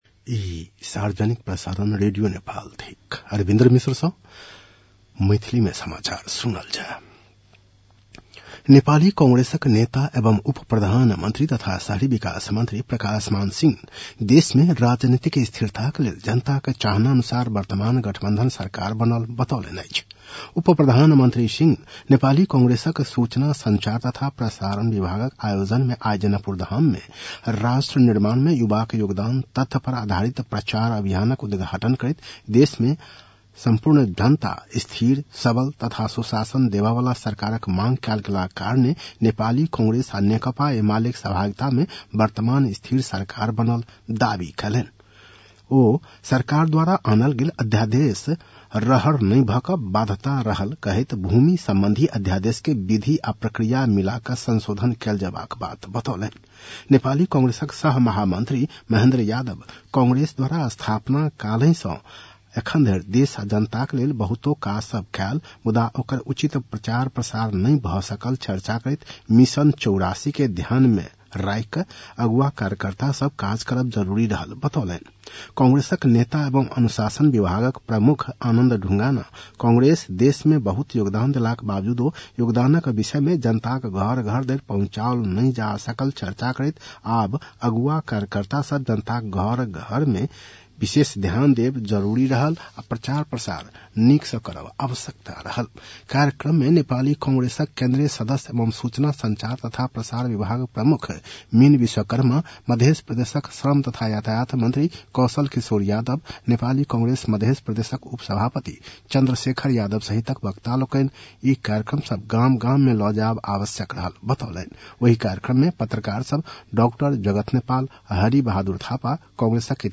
मैथिली भाषामा समाचार : ११ फागुन , २०८१